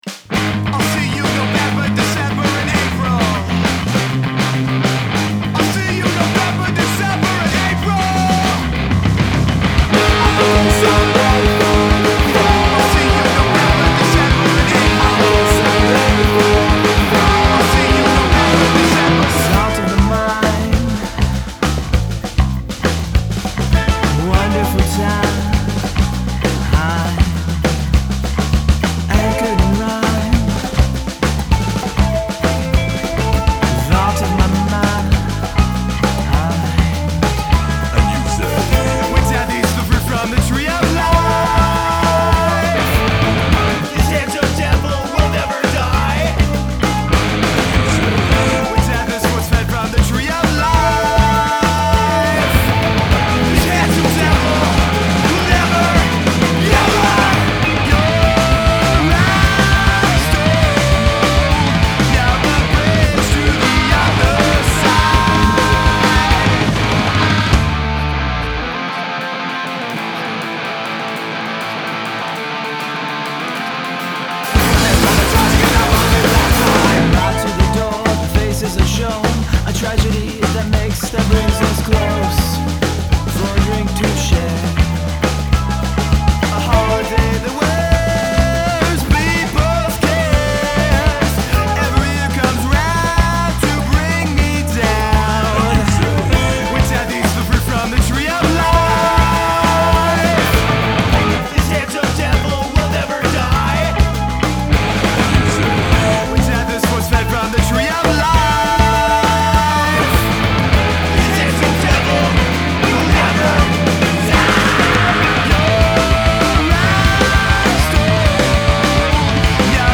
progressive hardcore band
Theremin
Tenor Sax